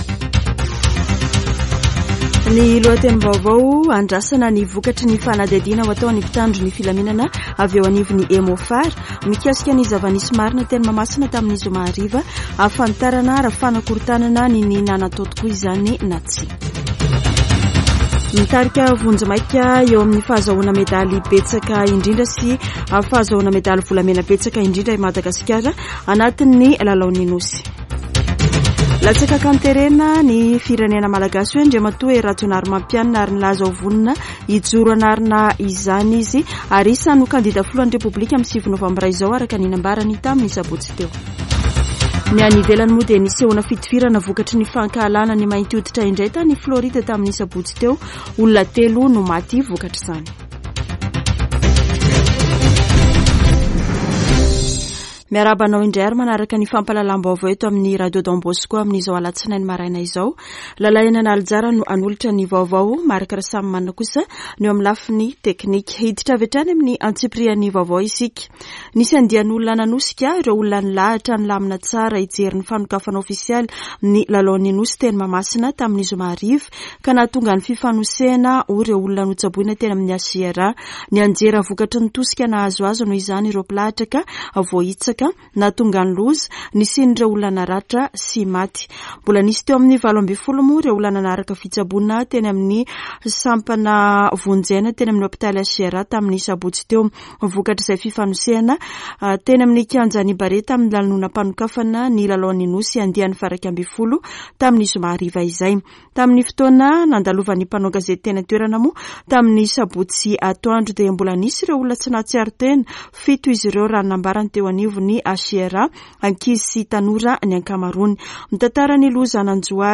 [Vaovao maraina] Alatsinainy 28 aogositra 2023